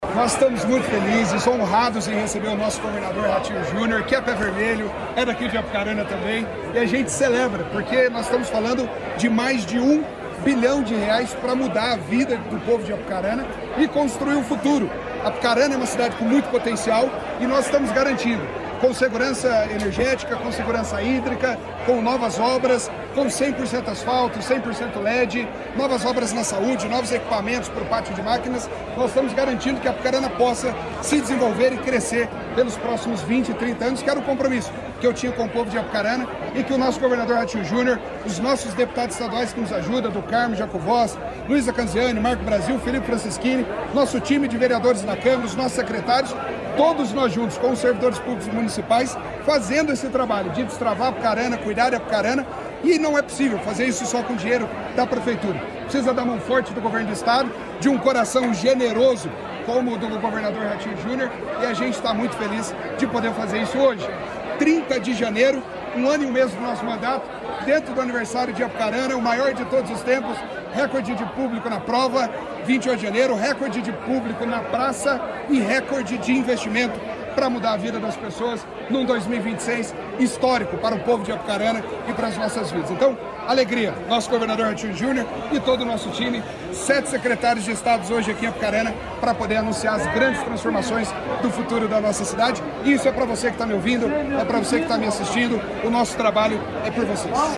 Sonora do prefeito de Apucarana, Rodolfo Mota, sobre o anúncio de investimentos para a cidade | Governo do Estado do Paraná